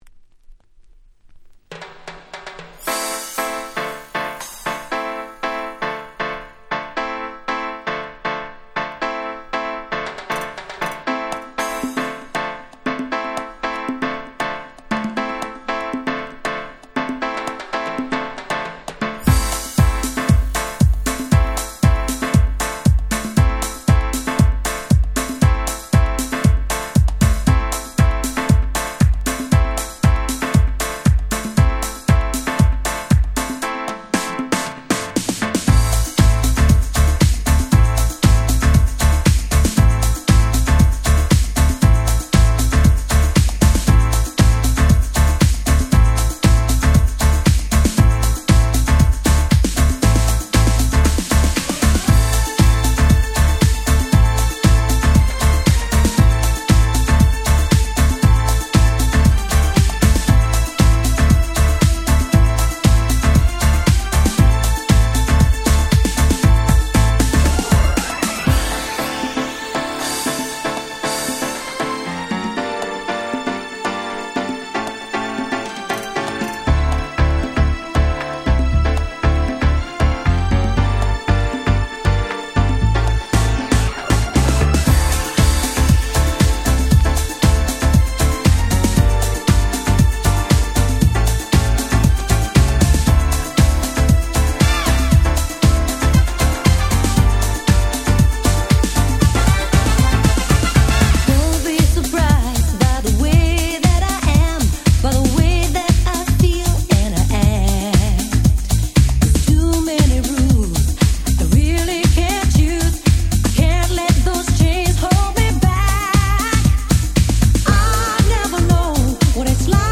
93' Nice UK R&B !!!
Club Mix